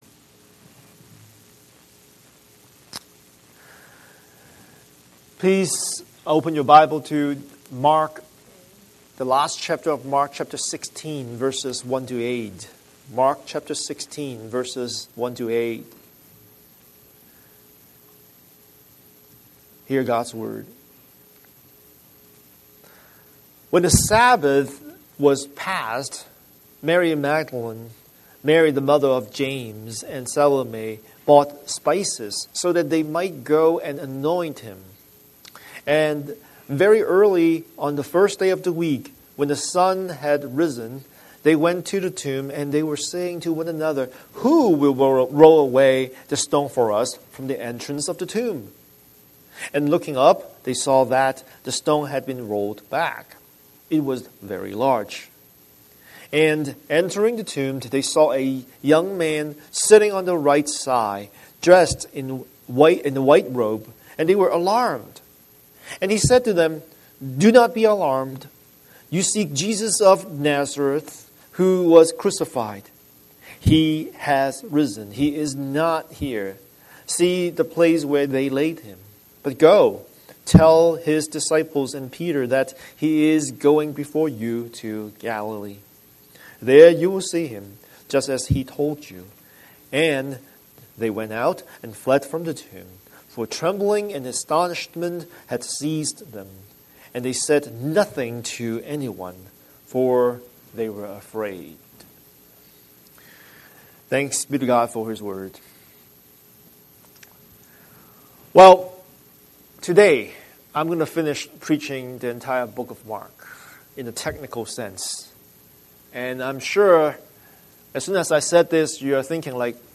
Scripture: Mark 16:1–8 Series: Sunday Sermon